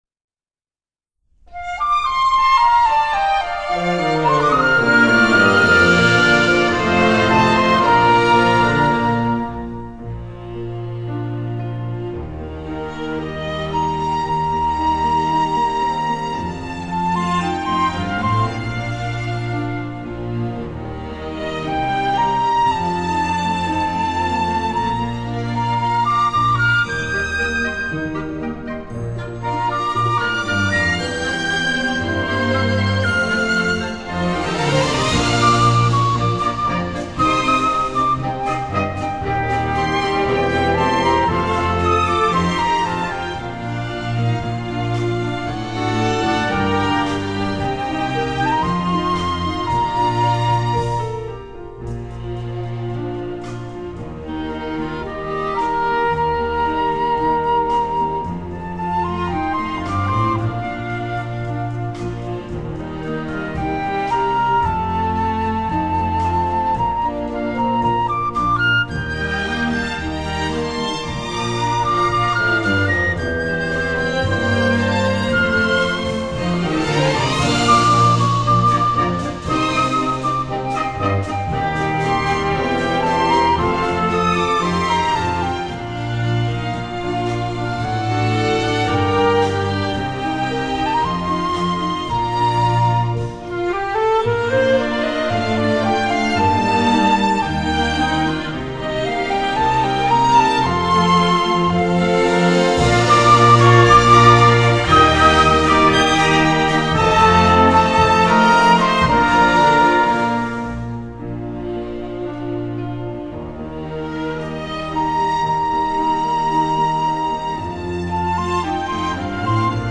オーケストラ伴奏